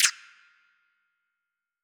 TMPerc_2.wav